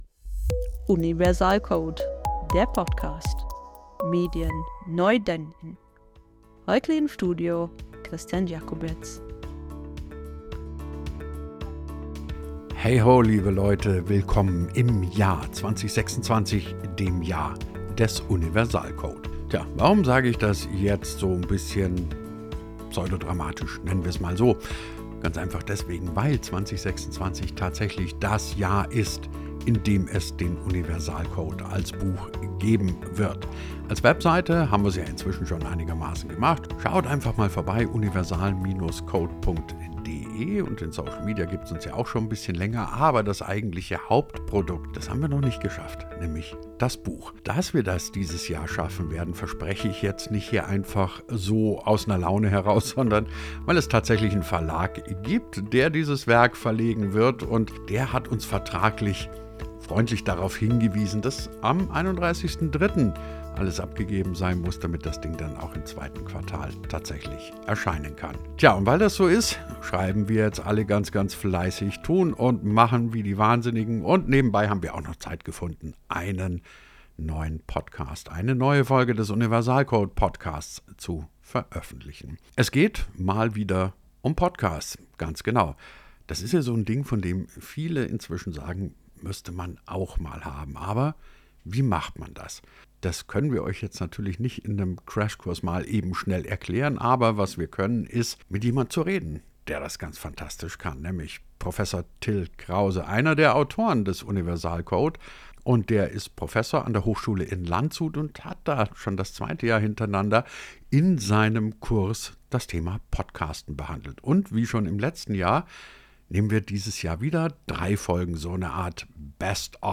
Auch in diesem Jahr hört ihr wieder drei ausgewählte Storys von Studenten und Studentinnen aus Landshut.